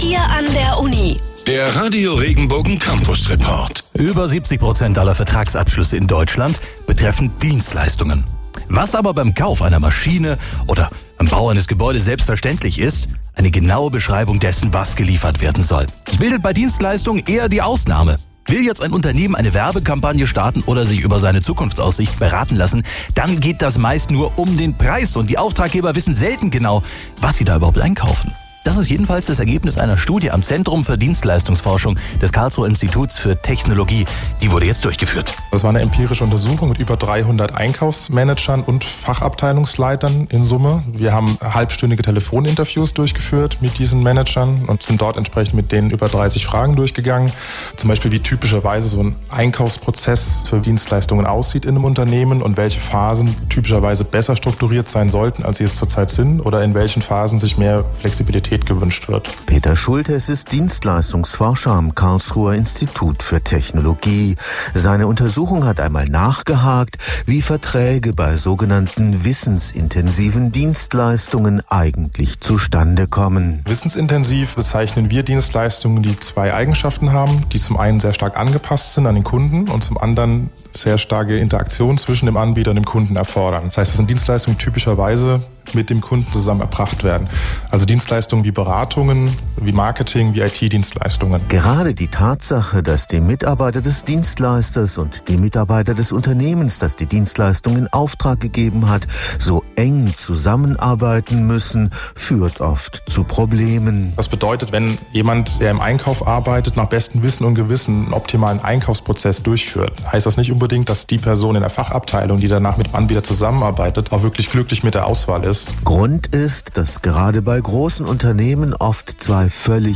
Interviewter